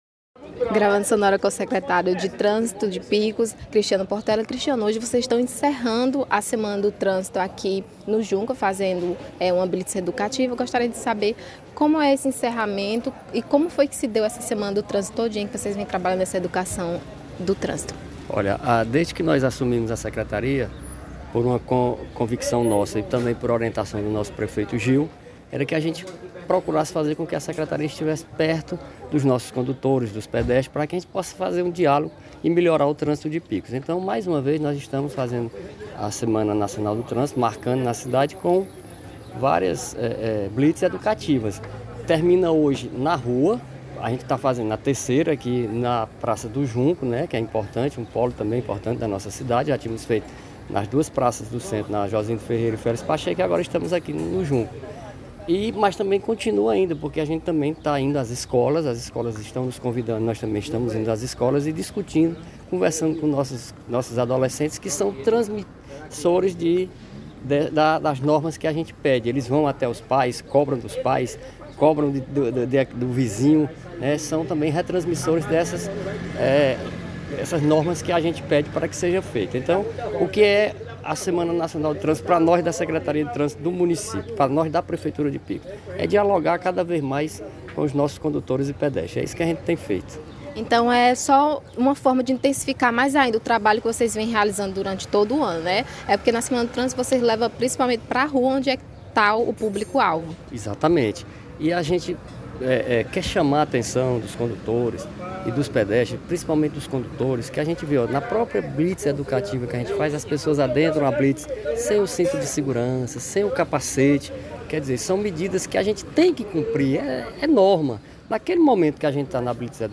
O Secretário de Trânsito, Transporte e Mobilidade Urbana de Picos, Cristiano Portela, falou sobre a ação de prevenção e fiscalização feita pela STTRAM.